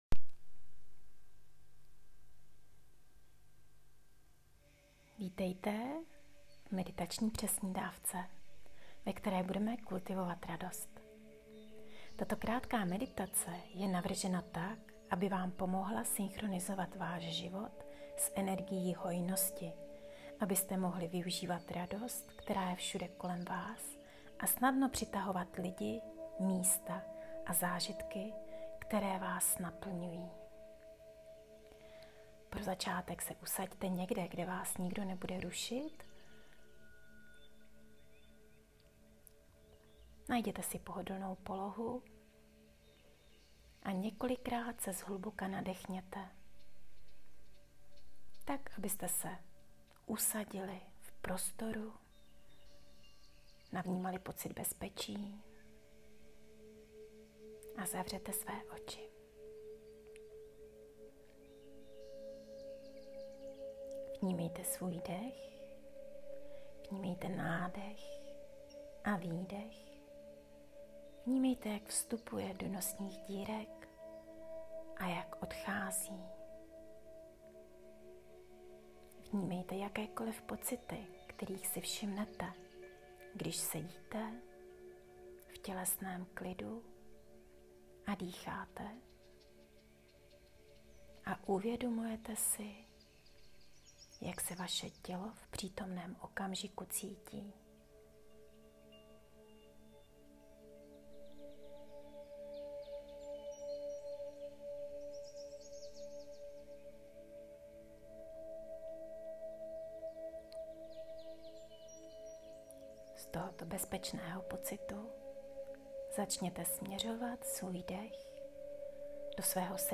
Meditační přesnídávka - 5 minut
Meditace - 5 minut